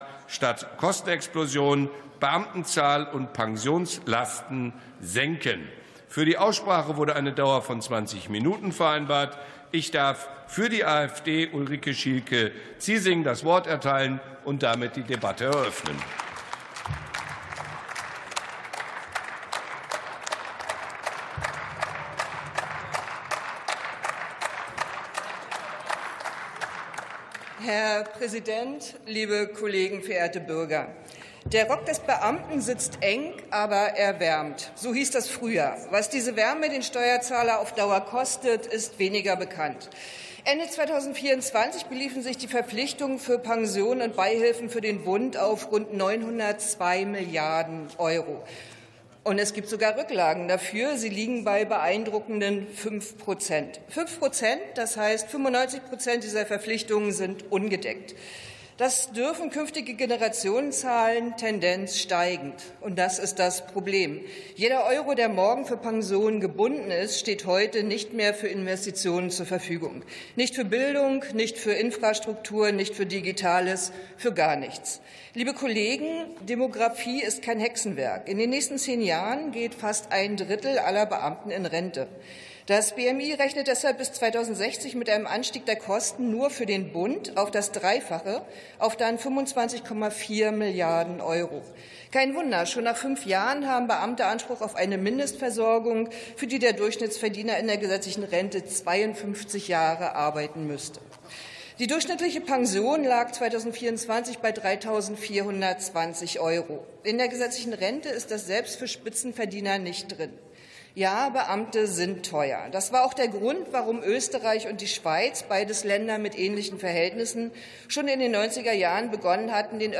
62. Sitzung vom 05.03.2026. TOP 18: Beschränkung der Verbeamtung, Pensionslasten ~ Plenarsitzungen - Audio Podcasts Podcast